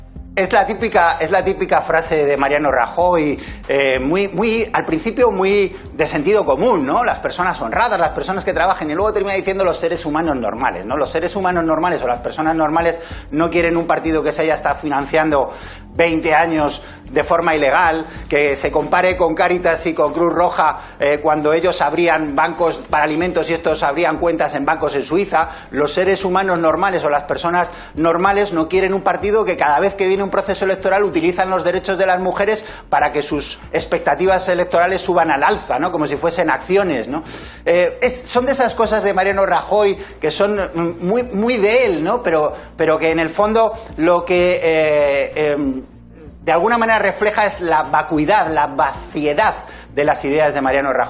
Antonio Hernando valora las declaraciones de Rajoy, el domingo 12 de abril de 2015, animando al PP a buscar el voto de "las personas normales". Entrevista en Las mañana de Cuatro TV